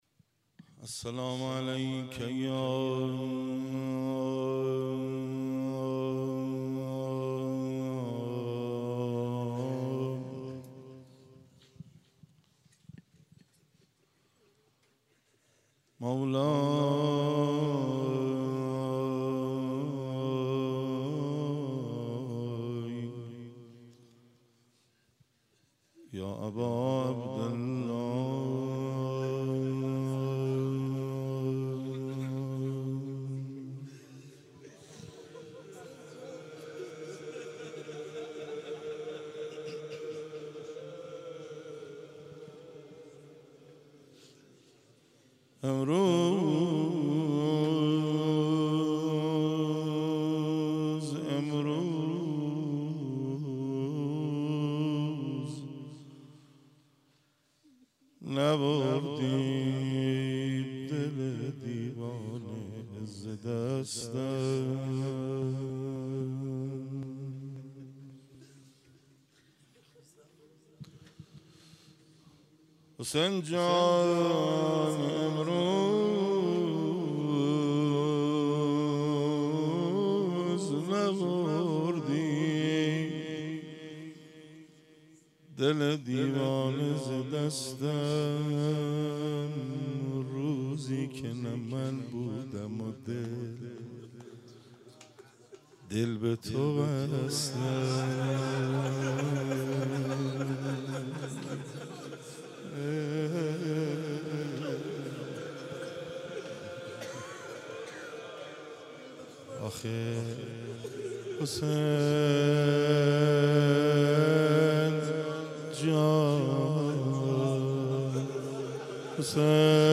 شب سوم رمضان 95